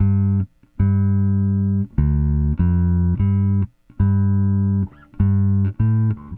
Weathered Bass 02.wav